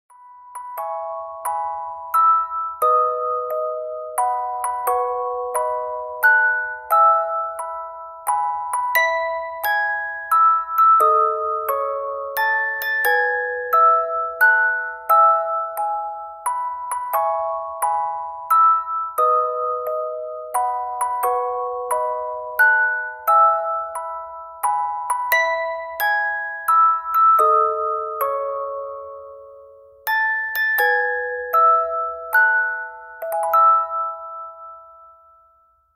• Качество: 192, Stereo
спокойные
красивая мелодия
инструментальные
Музыкальная шкатулка
"С днем рождения" в звучании музыкальной шкатулки